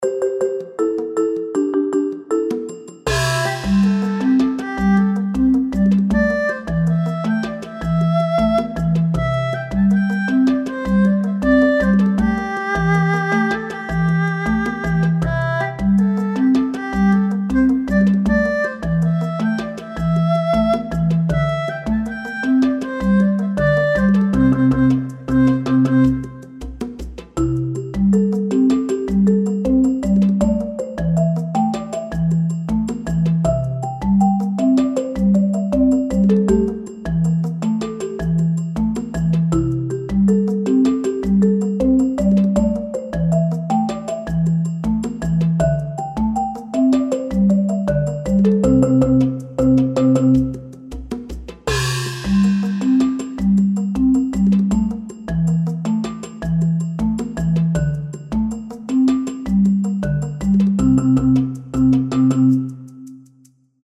ファンタジー系フリーBGM｜ゲーム・動画・TRPGなどに！
中華風マンボ（意味不明）いい感じのタイミングで掛け声を入れてみてね。